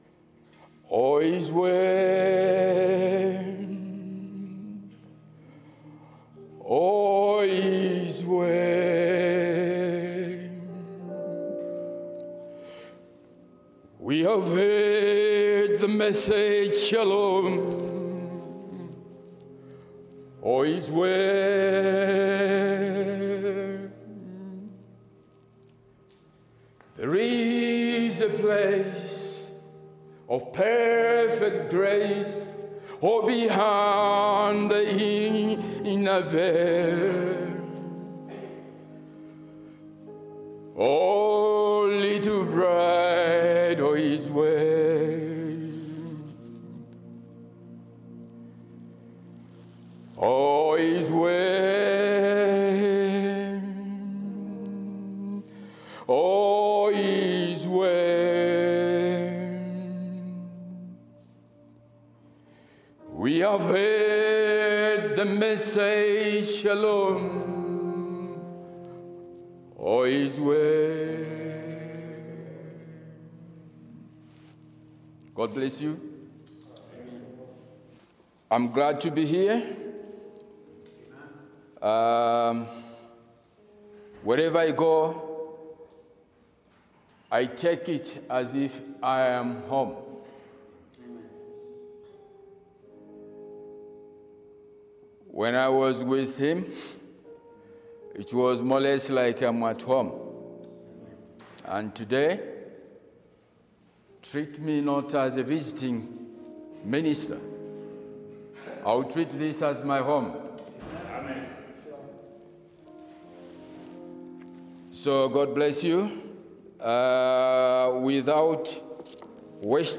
Friday meetings | Believers’ Assembly